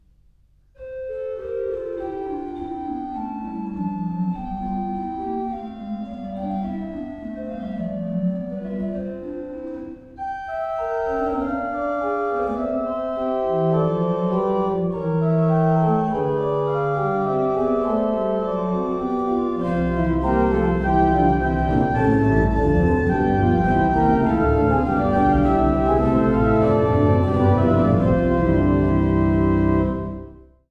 Geißler-Orgel Uebigau